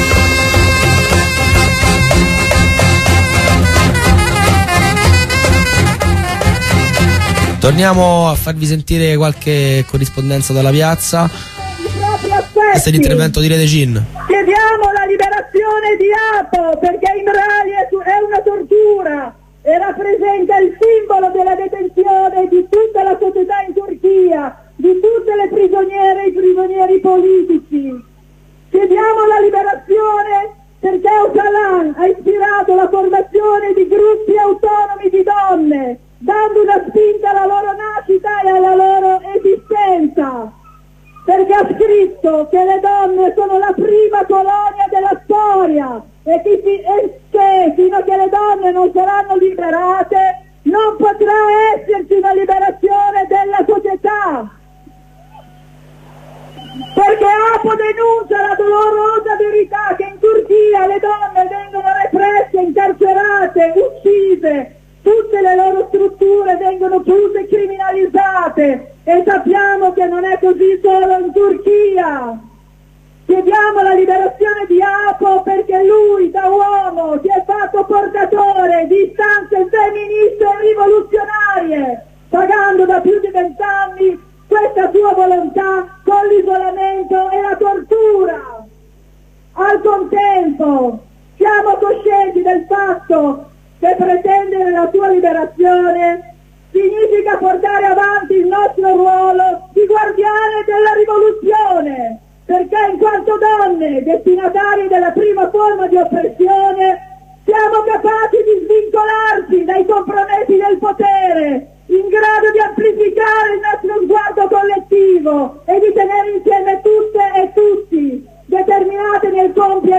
Libertà per Ocalan - interventi dal corteo
Serie di corrispondenze e contributi dalla manifestazione nazionale di Roma per la liberazione di Ocalan
corteo ocalan 2.ogg